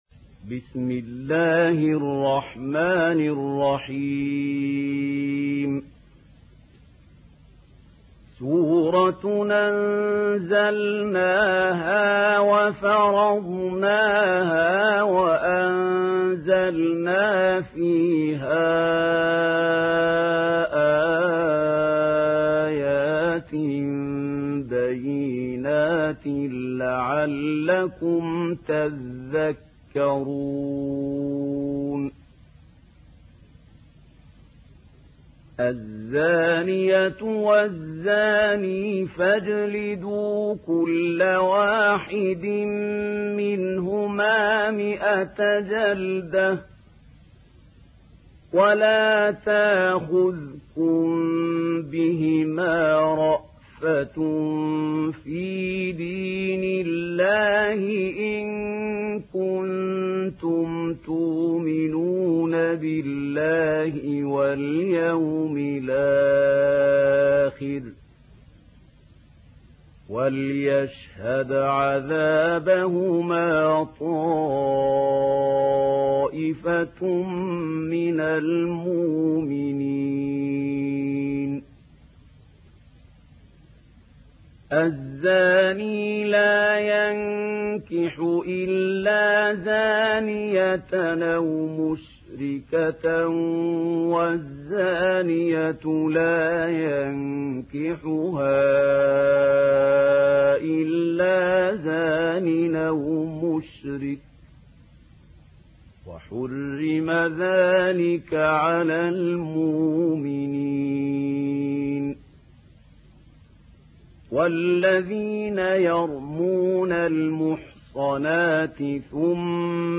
Surat An Nur mp3 Download Mahmoud Khalil Al Hussary (Riwayat Warsh)